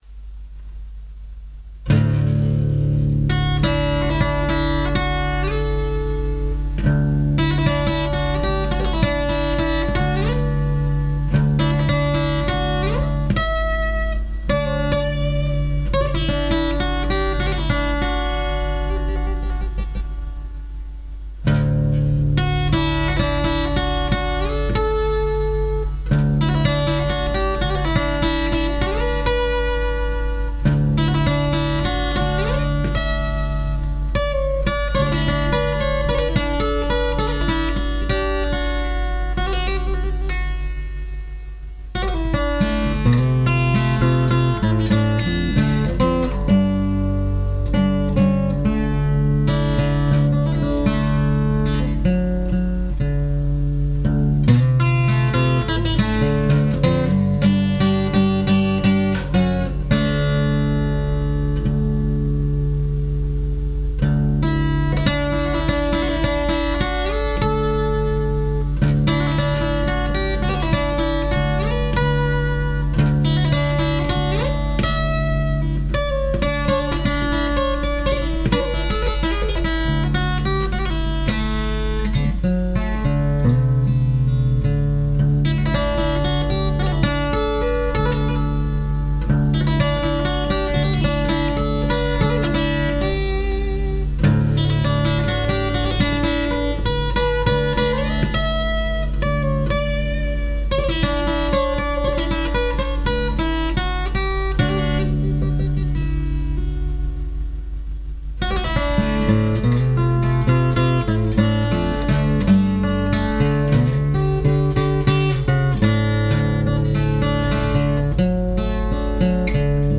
a non-slide version of the tune